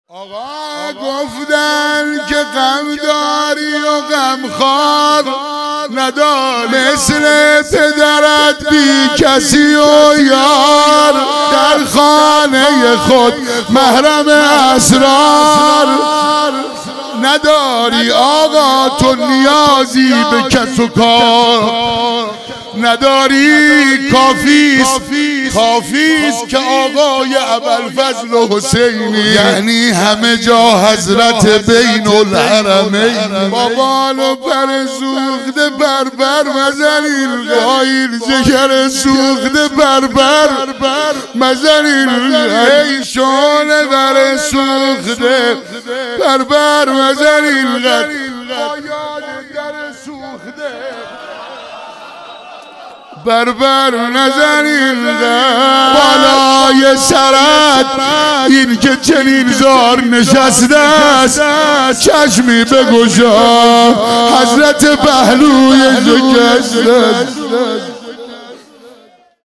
روضه امام حسن (ع)